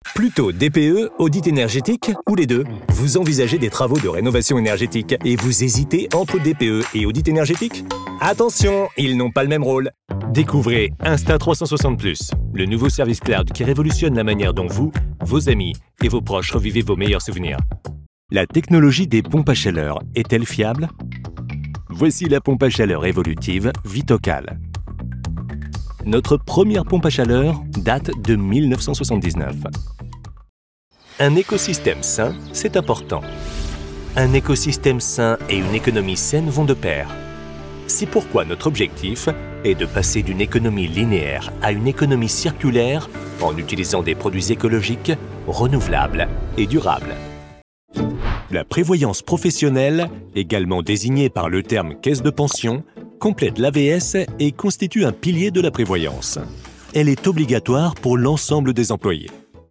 Zugänglich, Vielseitig, Zuverlässig, Warm
Erklärvideo